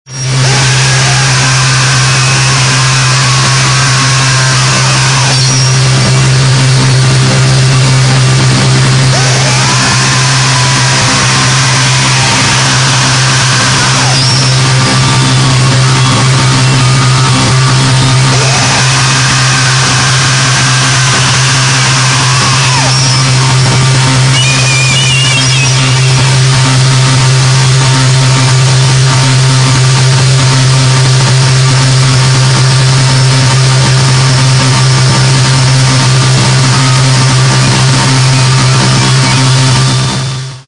with howling vocals and blood-curdling noise